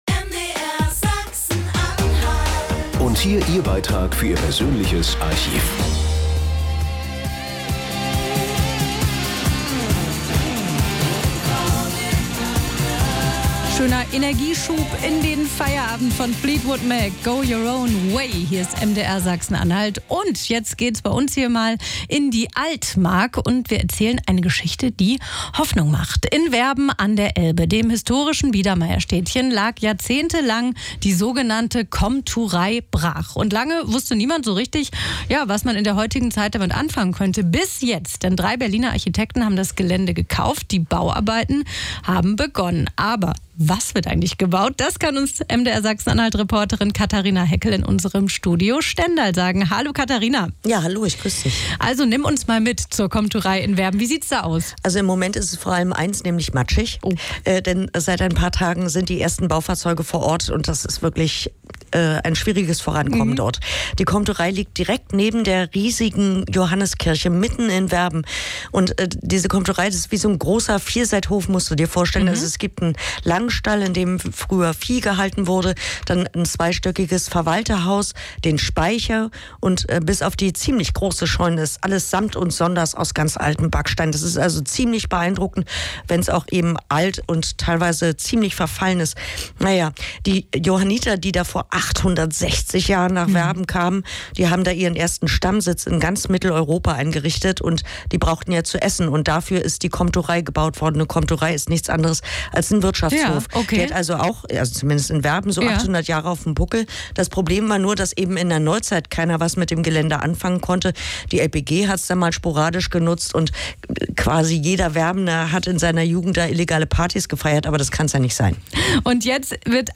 MDR, Radiobeitrag (11/2023)